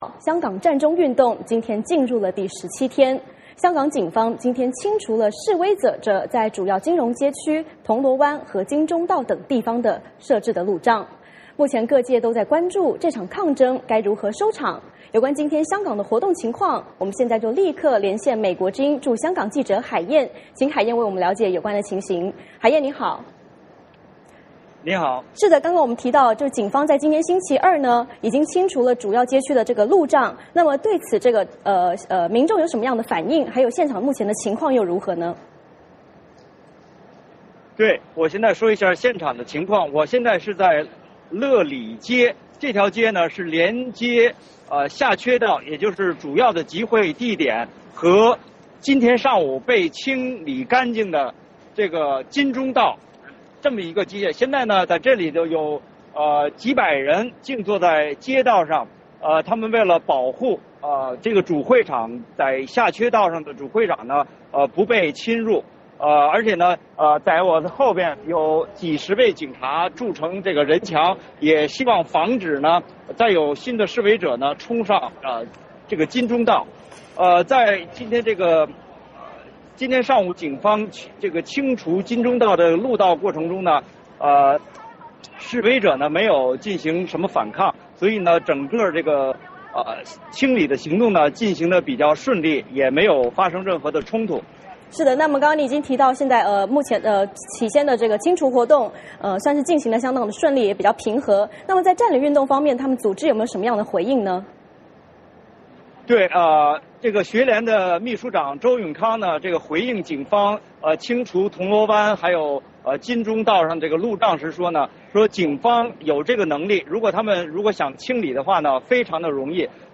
VOA连线：港警清除金钟路障 香港占中行动是否告一段落？